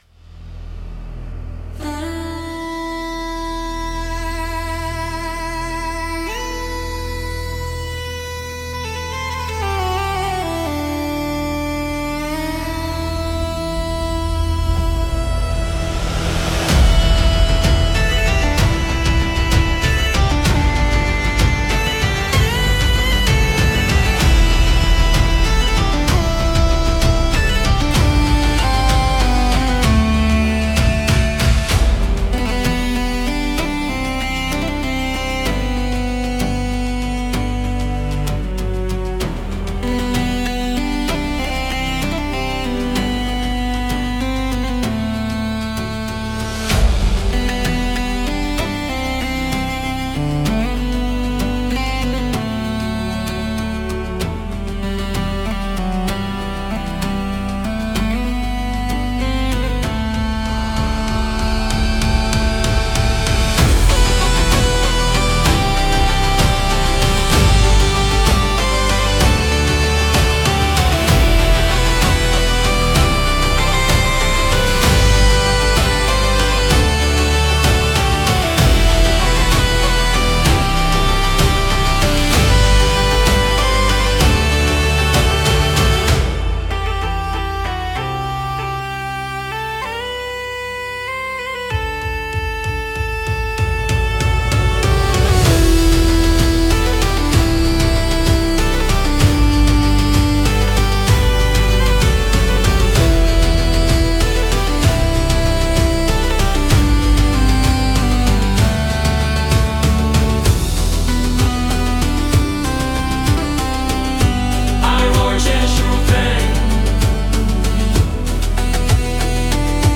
песня ai
Instrumental: